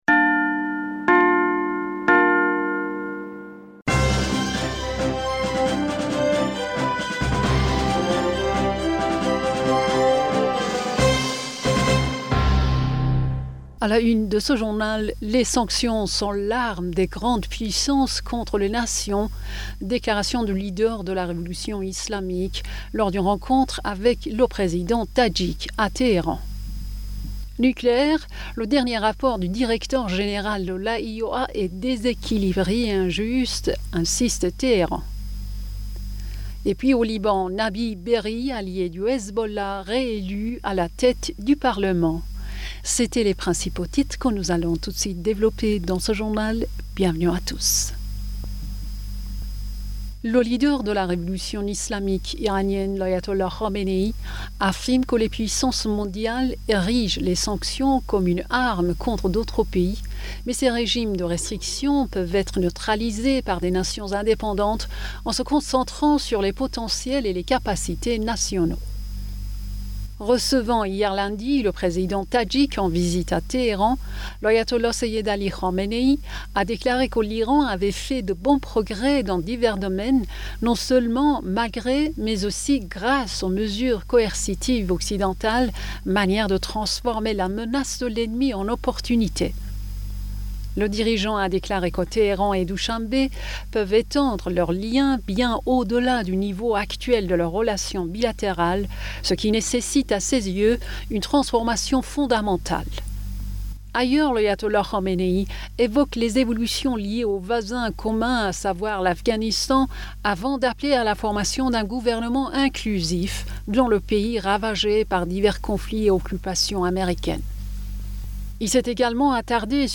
Bulletin d'information Du 31 Mai 2022